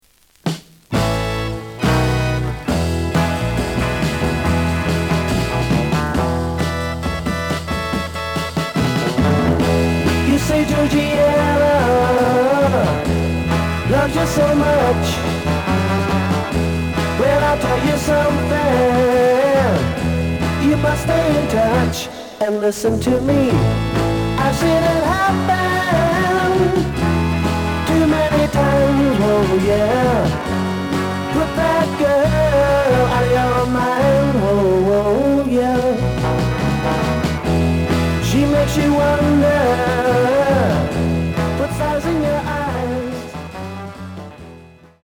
The audio sample is recorded from the actual item.
●Genre: Rock / Pop
Some noise on A side.)